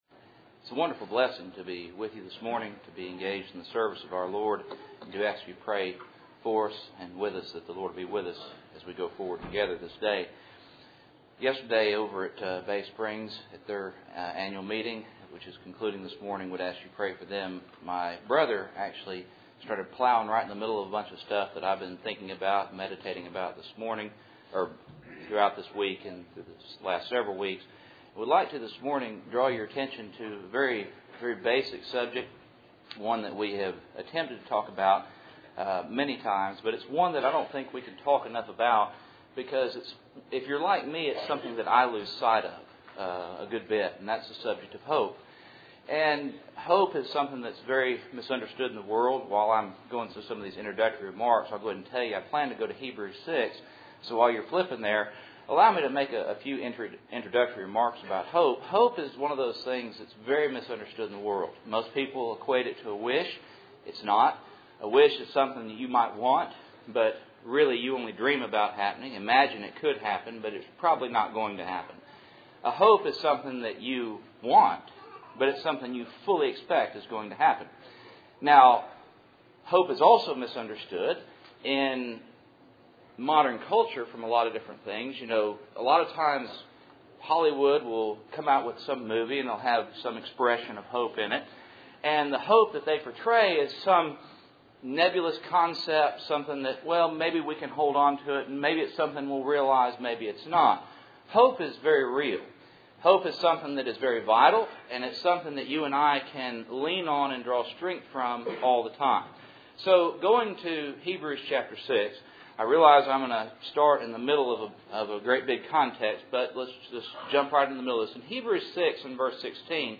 Hebrews 6:16-19 Service Type: Cool Springs PBC Sunday Morning %todo_render% « Pharisees